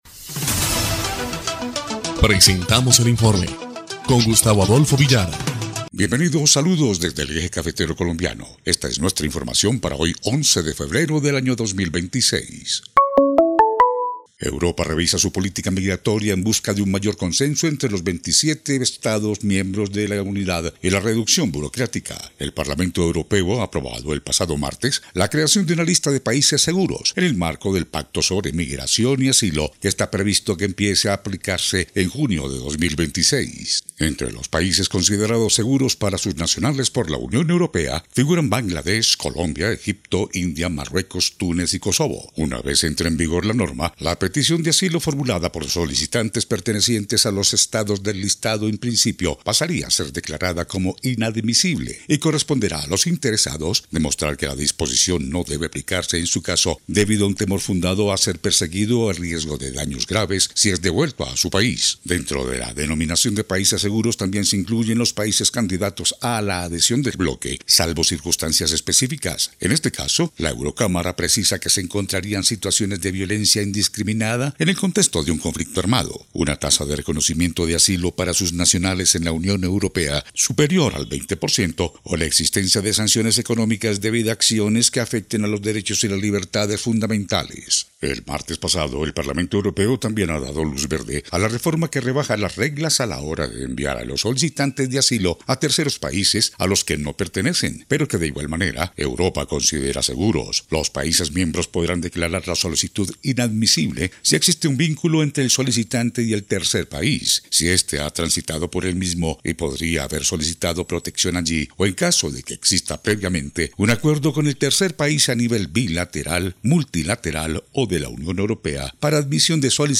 EL INFORME 1° Clip de Noticias del 11 de febrero de 2026